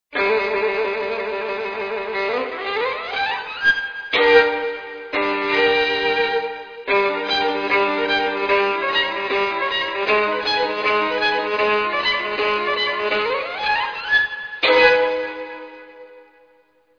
Violino
Penetrante, vibrante, versatile ed espressivo.
violino.mp3